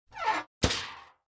Among Us Going Out Of Vent Sound Effect Free Download